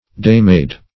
daymaid - definition of daymaid - synonyms, pronunciation, spelling from Free Dictionary Search Result for " daymaid" : The Collaborative International Dictionary of English v.0.48: Daymaid \Day"maid`\ (-m[=a]d`), n. A dairymaid.